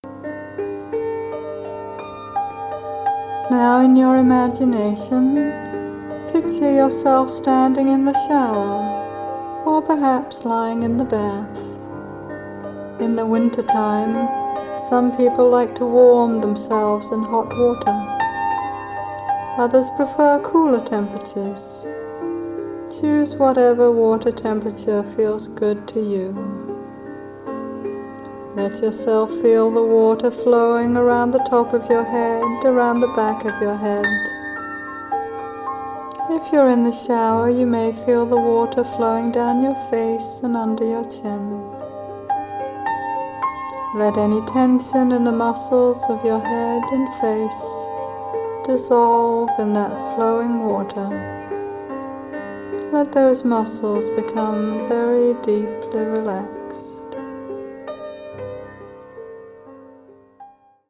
Seasons for Healing: Winter (Guided Meditation)
Piano and Synthesizer
Flute